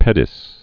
(pĕdĭs)